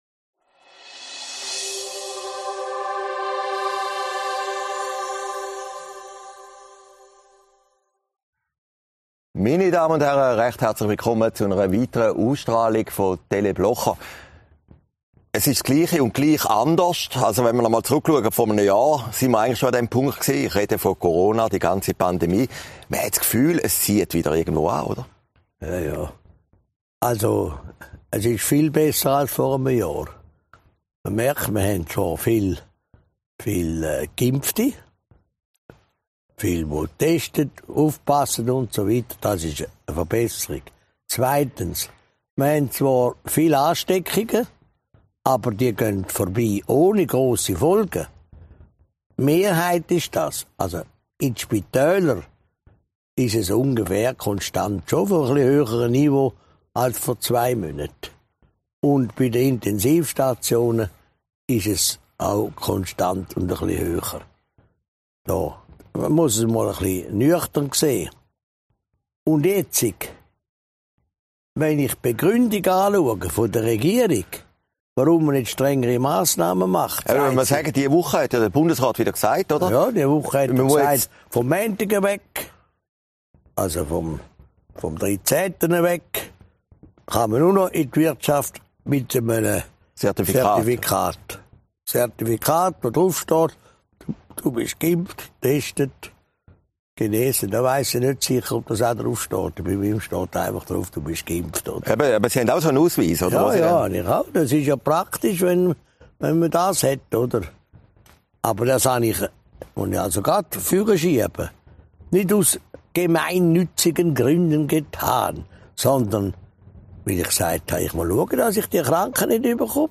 Sendung vom 10. September 2021, Herrliberg